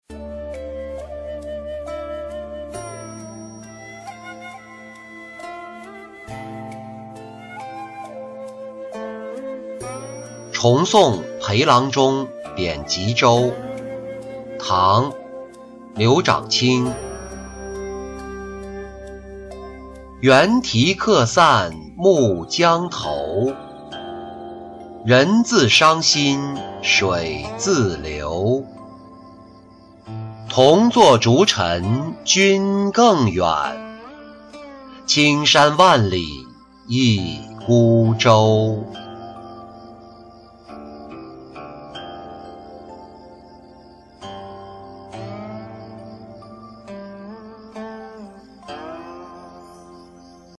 重送裴郎中贬吉州-音频朗读